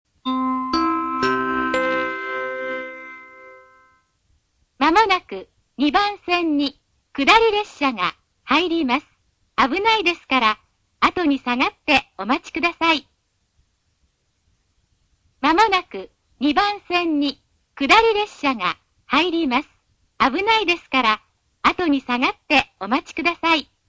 この駅は放送・メロディーとも音量が非常に大きいです。
２番線接近放送